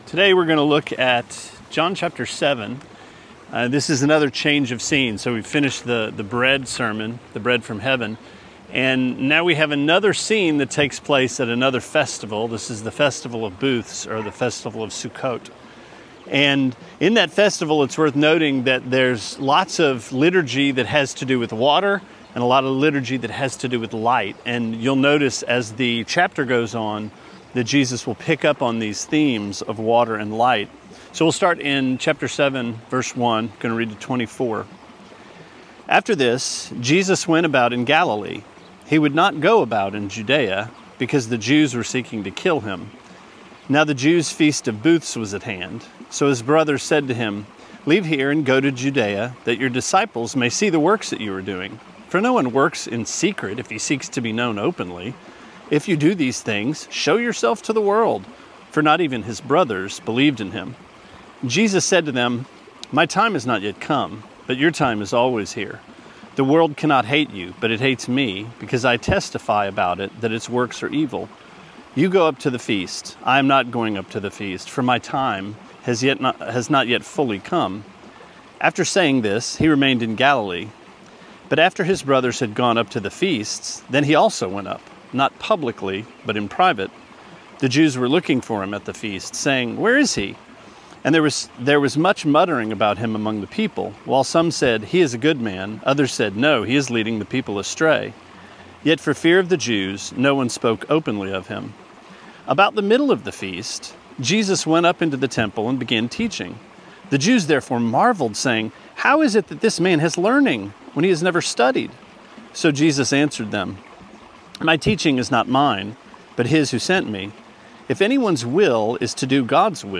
Sermonette 5/9: John 7:1-24: The Feast of Booths